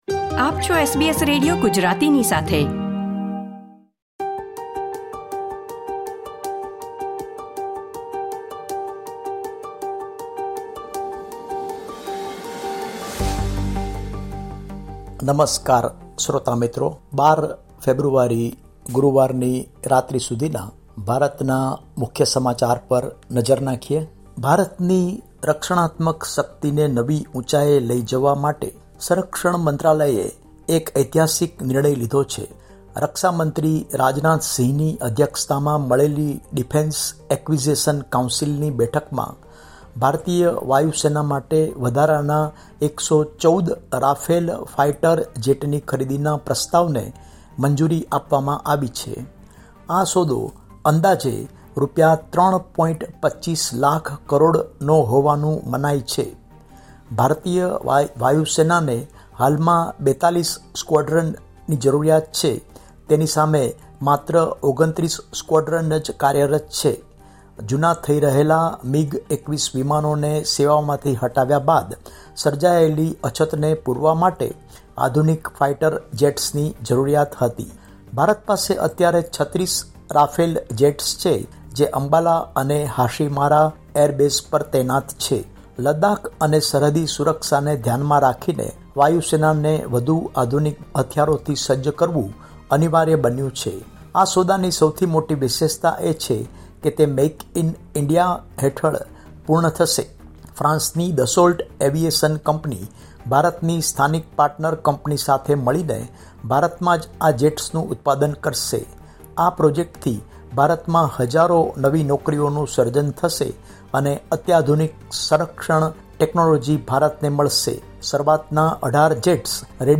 Listen to the top and latest news from India